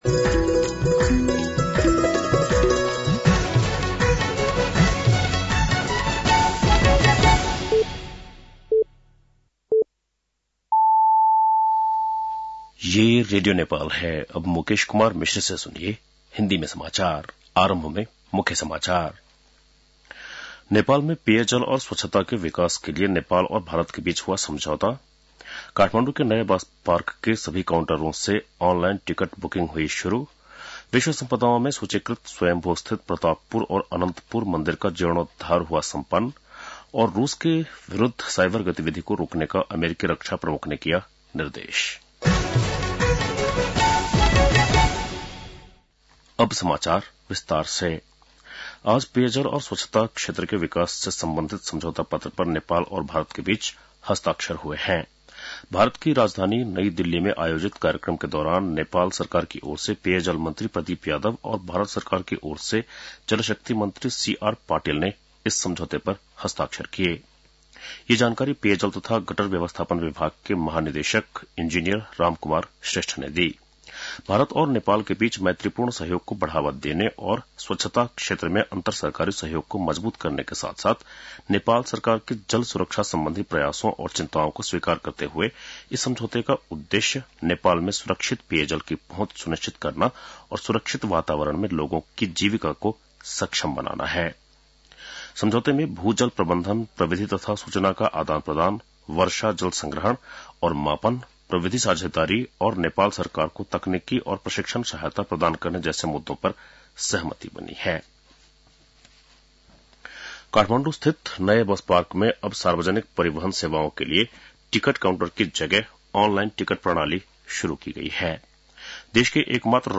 बेलुकी १० बजेको हिन्दी समाचार : २० फागुन , २०८१